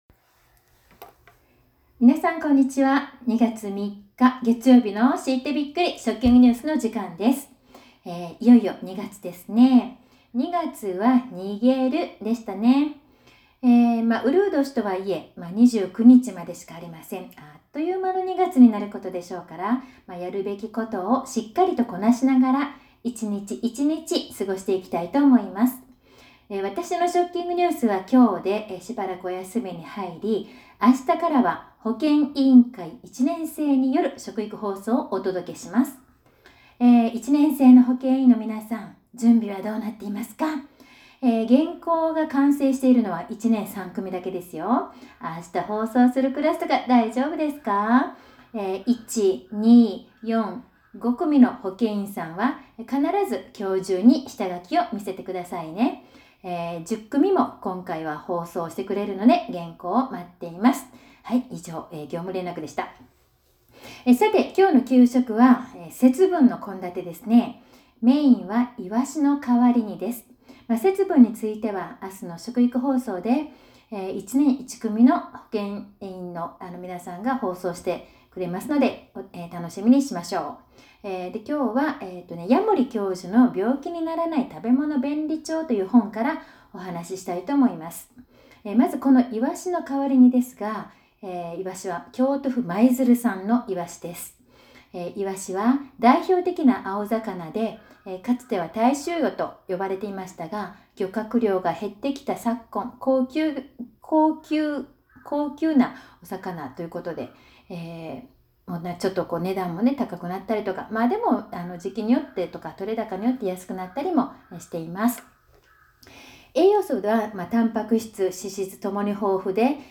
２月３日の給食＆食育放送